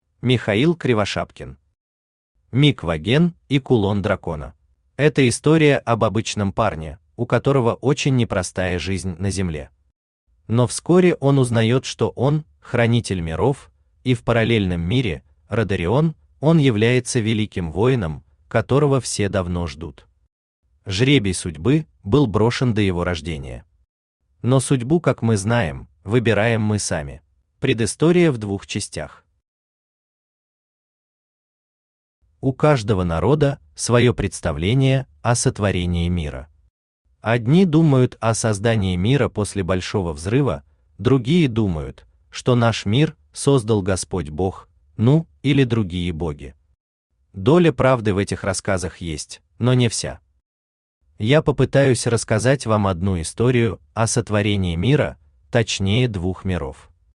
Аудиокнига Мик Воген и кулон дракона | Библиотека аудиокниг
Aудиокнига Мик Воген и кулон дракона Автор Михаил Васильевич Кривошапкин Читает аудиокнигу Авточтец ЛитРес.